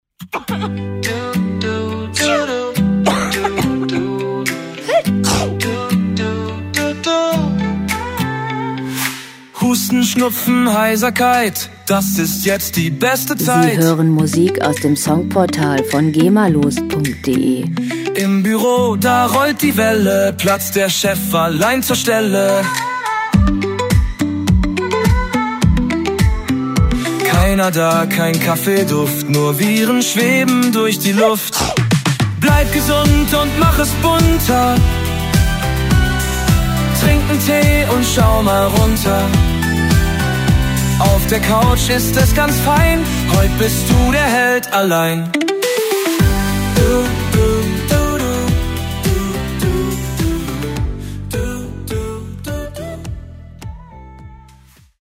Pop Musik aus der Rubrik: "Popwelt Deutsch"
Musikstil: Deutschpop
Tempo: 105 bpm
Tonart: G-Dur
Charakter: charmant, ironisch
Instrumentierung: Pop-Sänger, Gitarren, Synthesizer